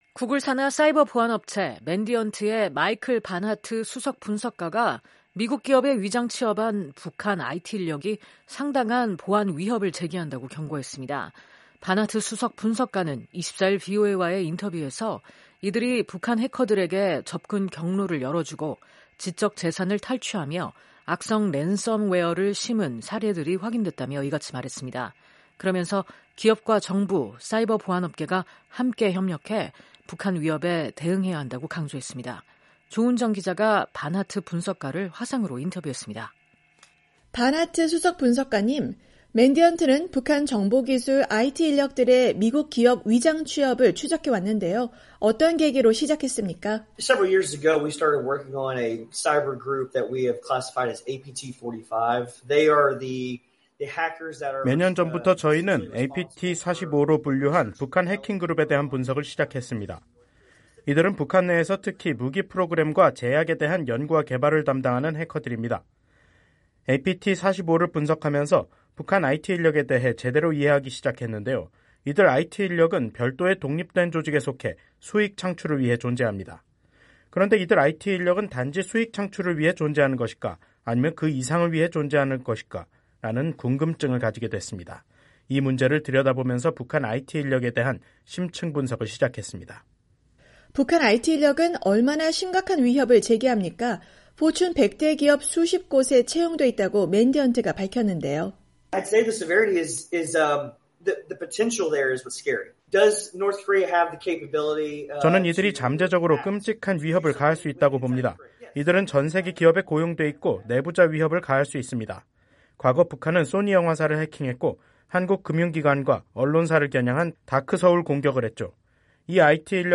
[인터뷰